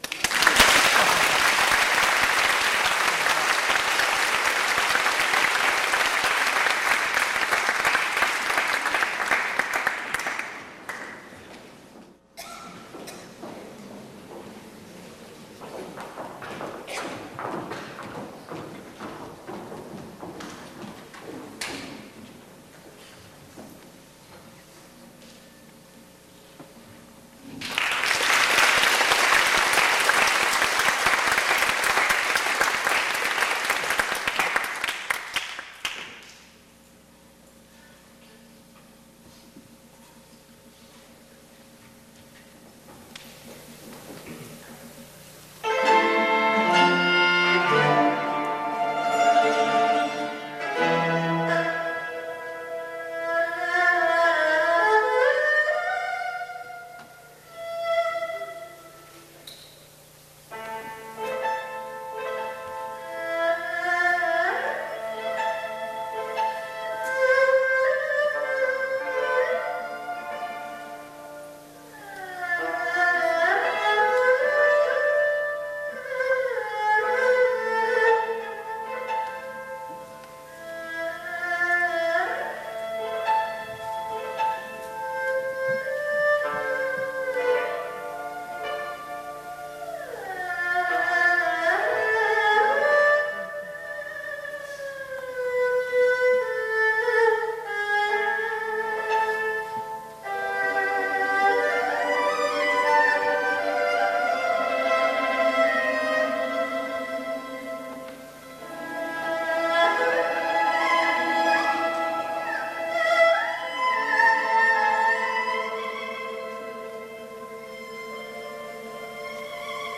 胡琴
台湾首张个人胡琴现场演奏专辑(DVD)
DVD→前卫、大胆、创新。